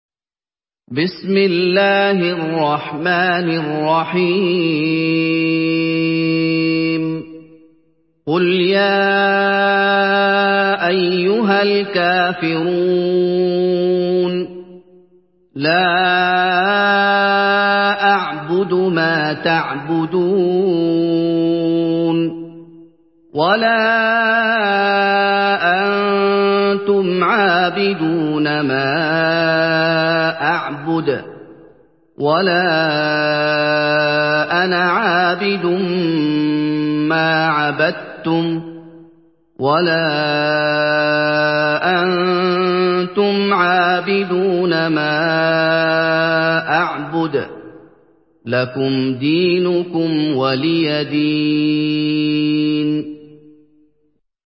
سورة الكافرون MP3 بصوت محمد أيوب برواية حفص عن عاصم، استمع وحمّل التلاوة كاملة بصيغة MP3 عبر روابط مباشرة وسريعة على الجوال، مع إمكانية التحميل بجودات متعددة.
مرتل حفص عن عاصم